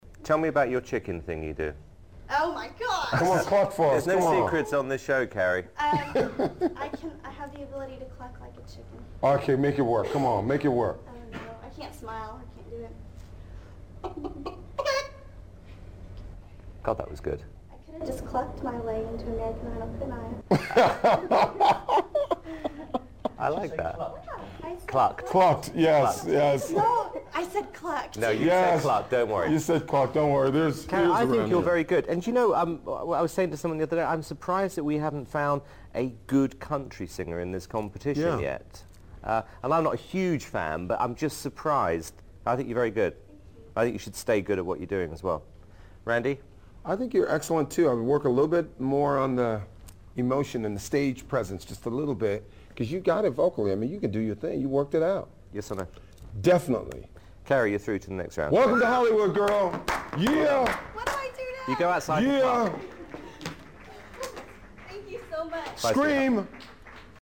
Carrie Underwood clucking and reaction